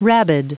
Prononciation du mot rabid en anglais (fichier audio)
Prononciation du mot : rabid